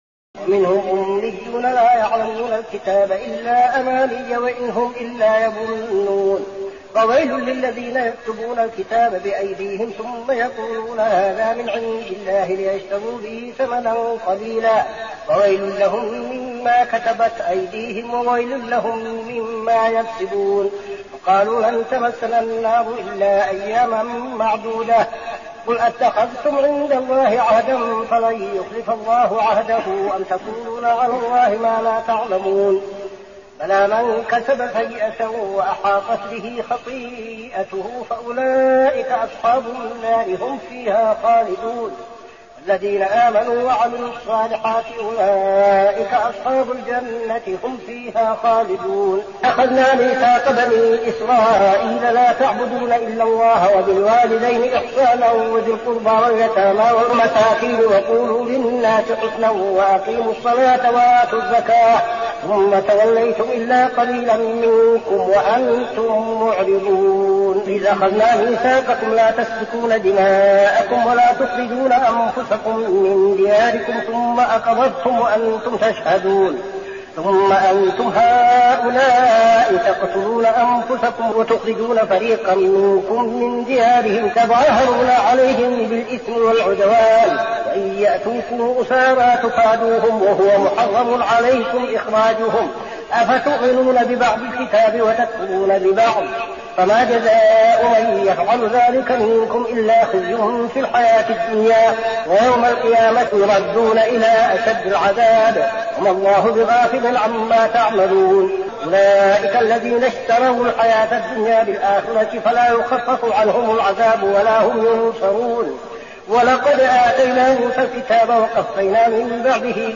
صلاة التراويح ليلة 1-9-1402هـ سورة البقرة 78-141 | Tarawih prayer Surah Al-Baqarah > تراويح الحرم النبوي عام 1402 🕌 > التراويح - تلاوات الحرمين